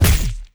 impact_projectile_006.wav